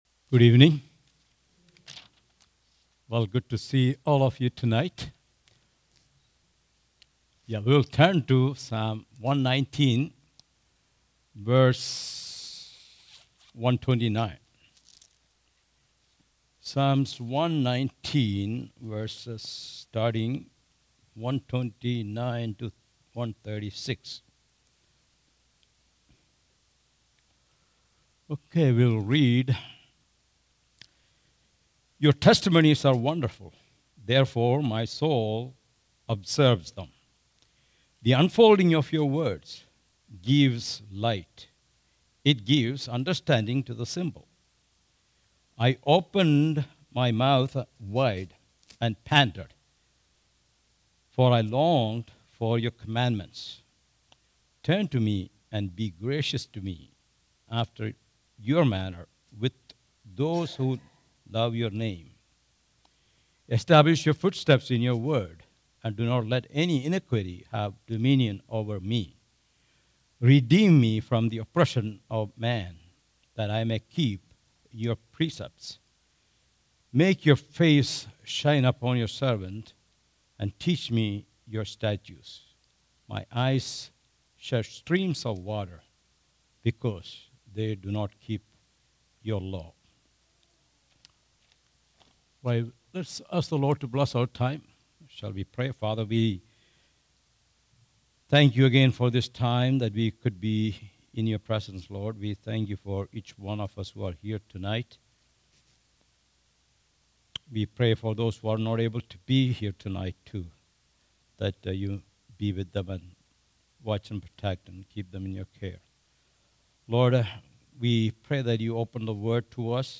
All Sermons Psalm 119:129-136